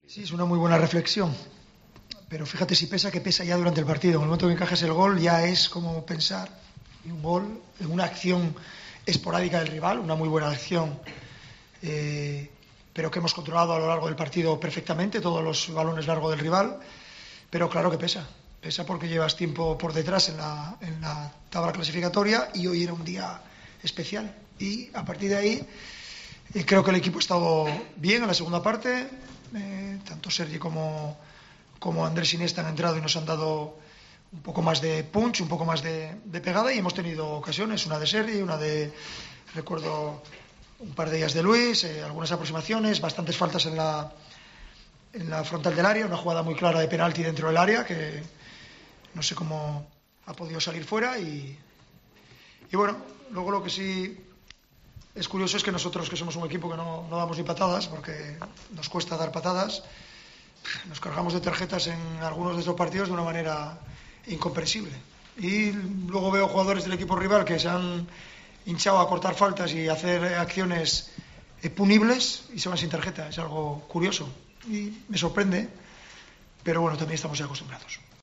El técnico del Barcelona valoró la derrota ante el Málaga en rueda de prensa y criticó algunas acciones arbitrales.